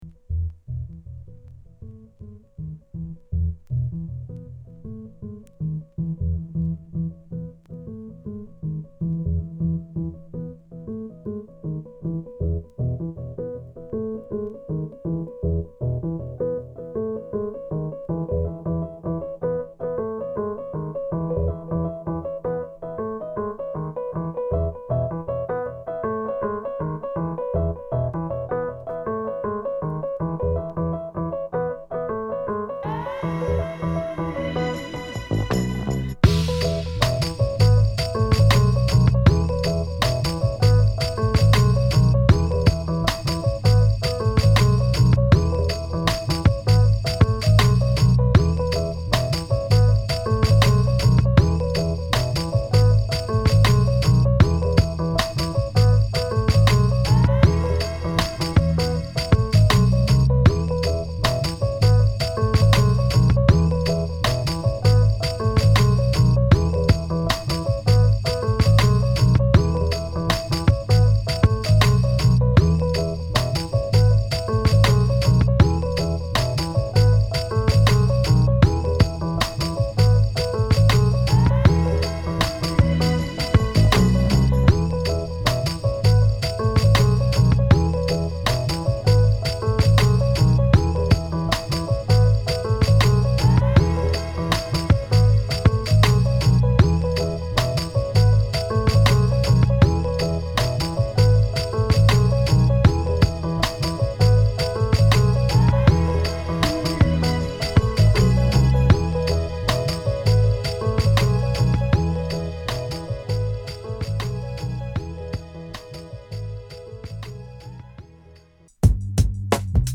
アルバム通して極上ダウンテンポ〜チルビーツを満載！